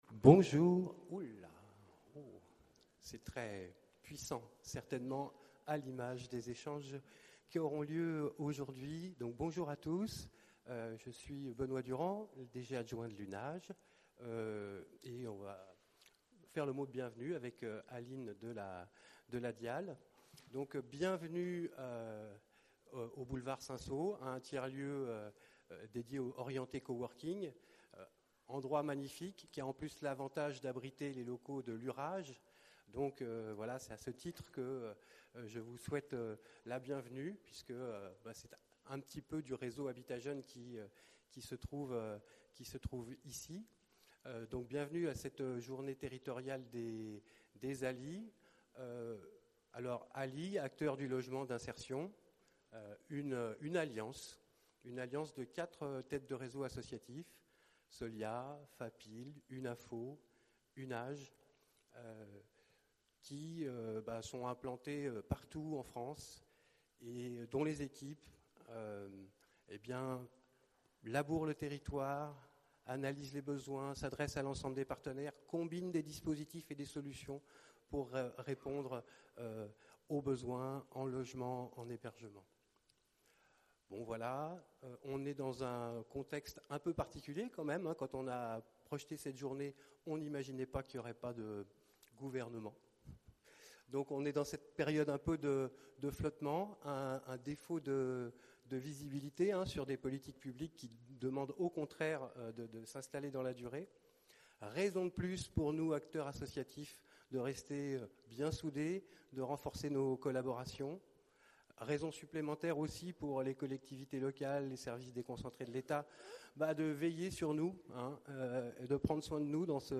Le 10 décembre à Lille, pour aborder un enjeu crucial : la fluidité entre hébergement et logement, avec la participation de la DIHAL. Les échanges ont été riches, grâce notamment à l’intervention de notre adhérent le GRAAL, qui a su mettre en lumière des solutions concrètes et des leviers d’action, mais aussi rappeler que, malgré la mobilisation de l’ensemble des acteurs, l’offre de logements abordables et adaptés reste globalement bloquée.
Rencontre-territoriale-Lille-des-ALI-replay-web.mp3